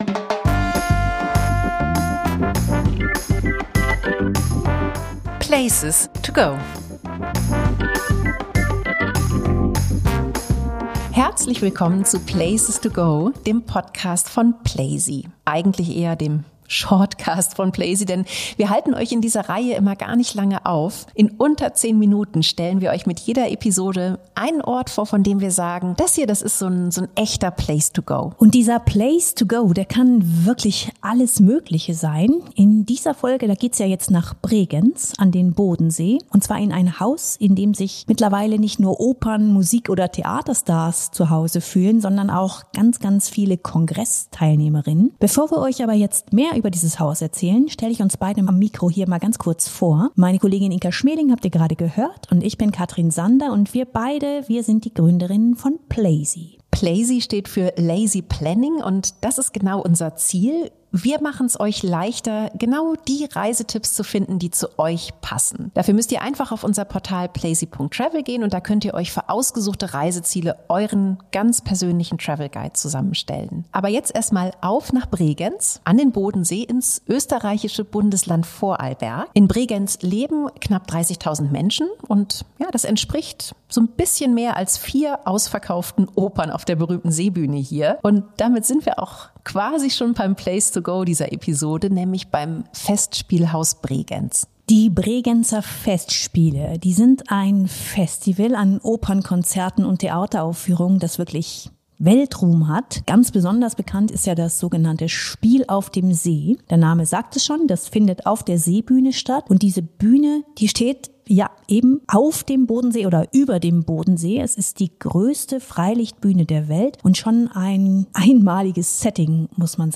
Interview-Gast